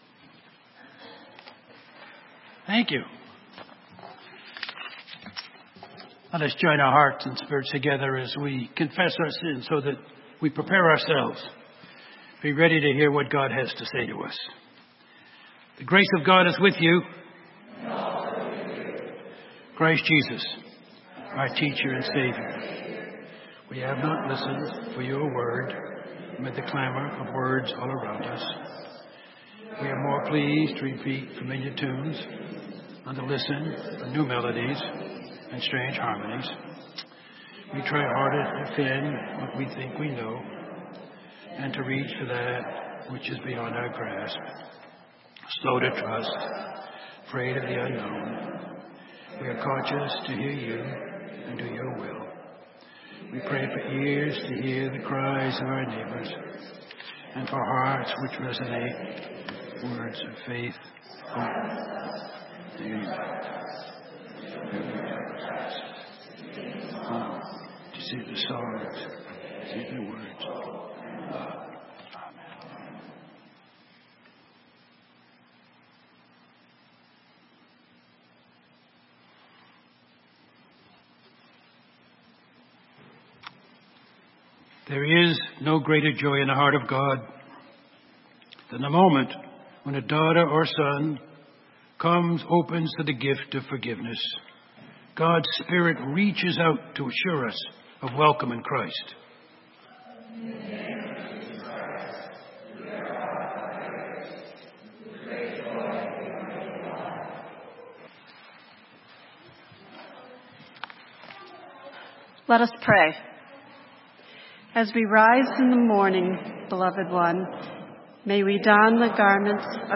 Sermon:A Word to the Wise and the Wicked - St. Matthews United Methodist Church